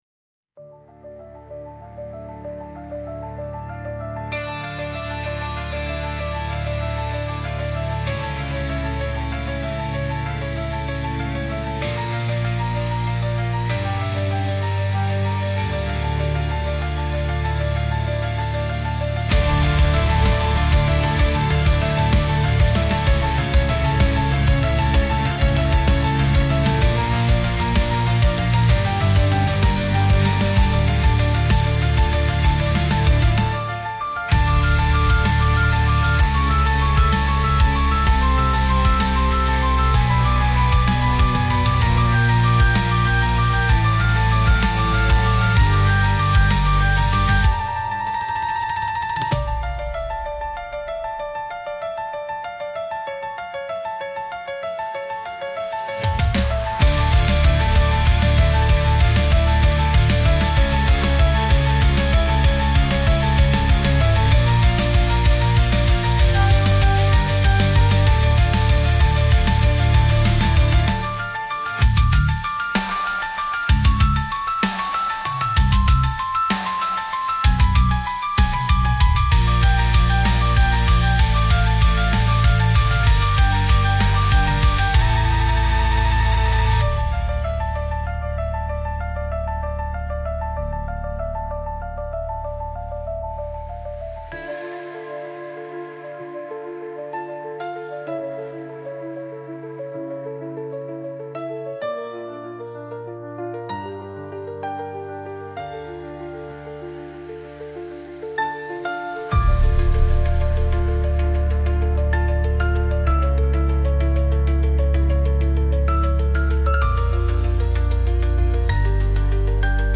Ai music
本楽曲は、sunoによって作成されました。